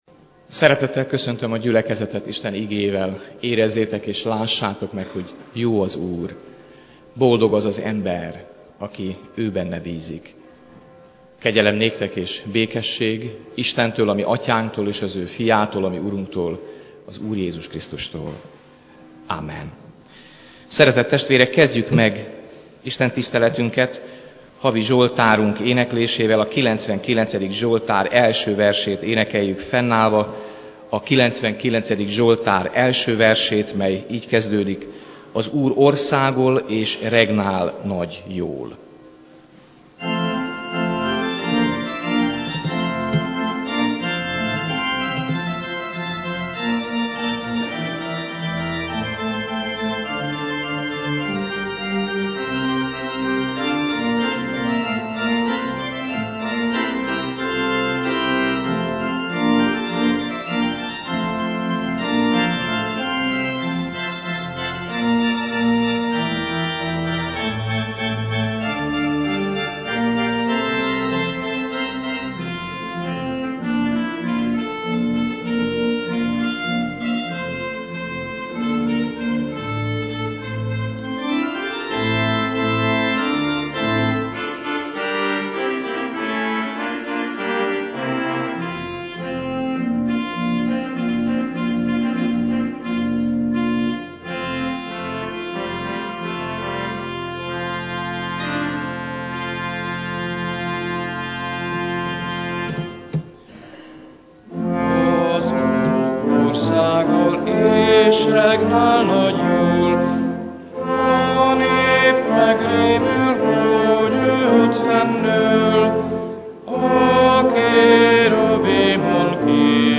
Igehirdetések Világos utakon